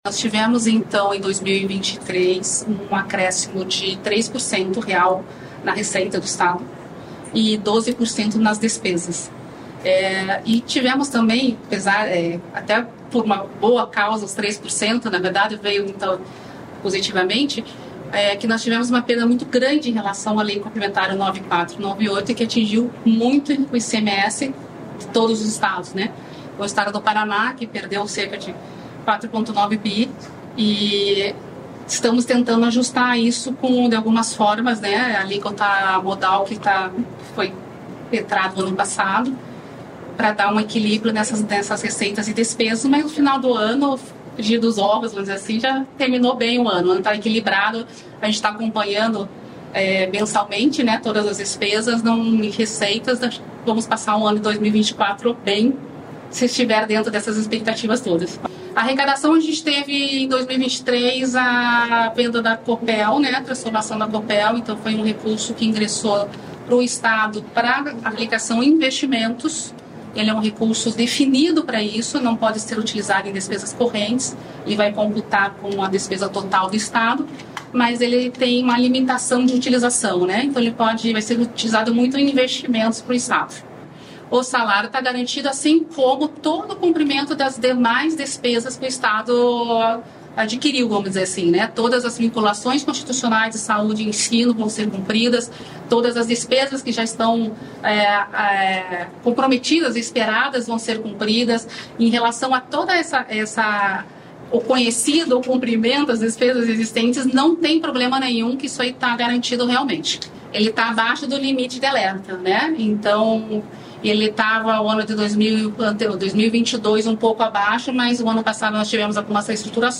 Sonora da diretora-geral da Secretaria da Fazenda, Marcia do Valle, sobre a divulgação do Relatório de Gestão Fiscal de 2023
MARCIA DO VALLE - AUDIÊNCIA PÚBLICA.mp3